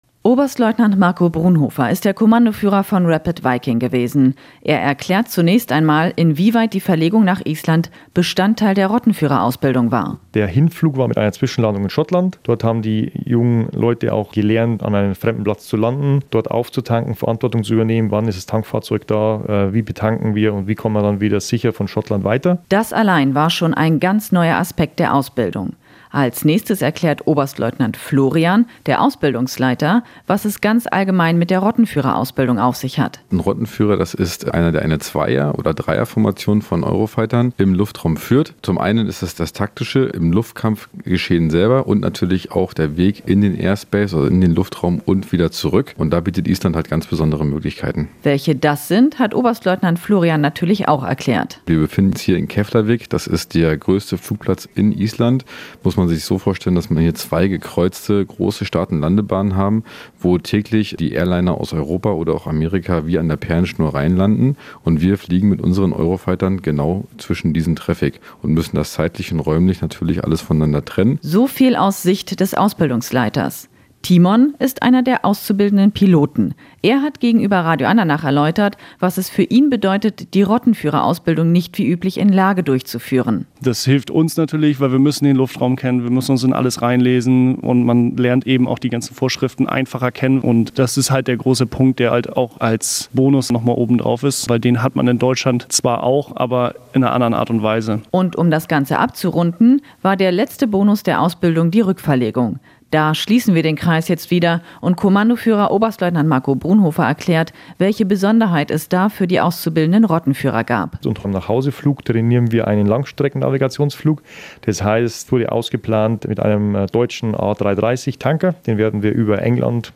Eurofighter wird von Bodenpersonal in Parkpostion eingewiesen
Am 28. Juli 2023 sind die sechs Eurofighter auf der Air Base Keflavik in Island gelandet. Hier weist die Wartungscrew einen Eurofighter in seine Parkposition vor dem Shelter ein.